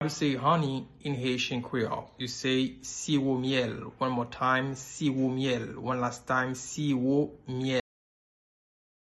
Listen to and watch “Siwo myèl” audio pronunciation in Haitian Creole by a native Haitian  in the video below:
15.How-to-say-Honey-in-Haitian-Creole-–-Siwo-myel-pronunciation.mp3